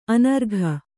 ♪ anargha